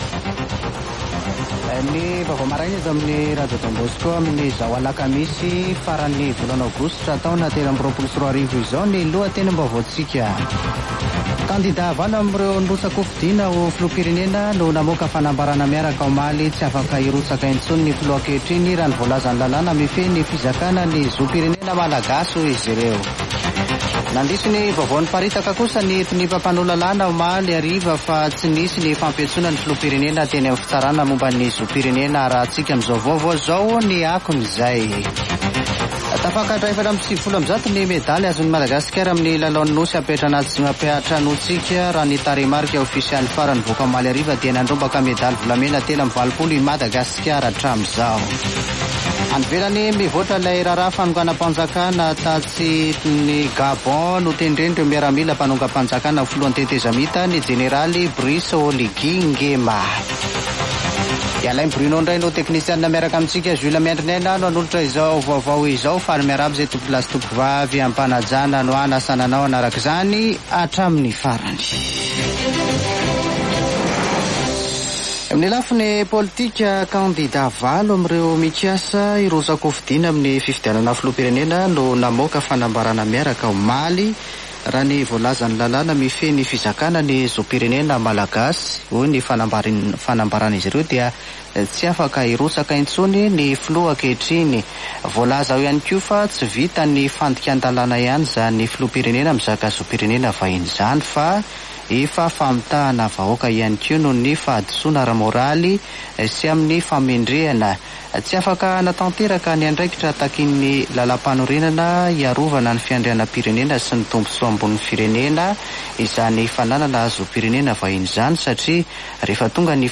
[Vaovao maraina] Alakamisy 31 aogositra 2023